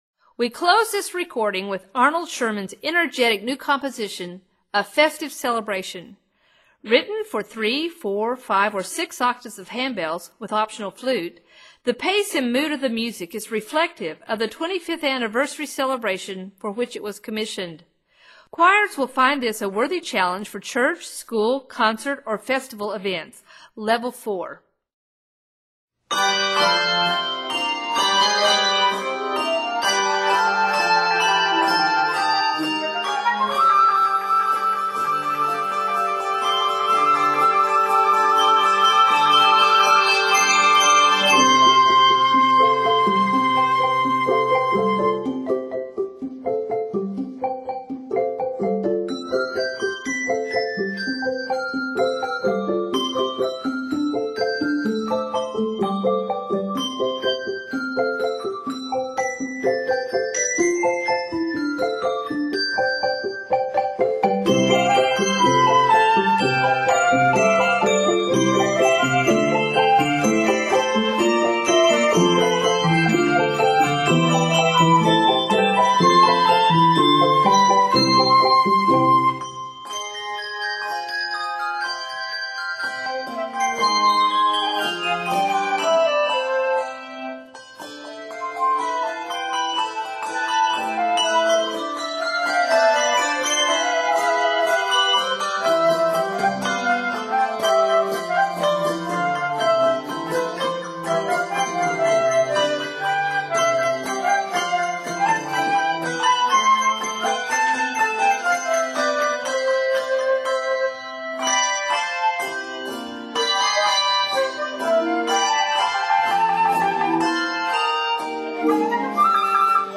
N/A Octaves: 3-6 Level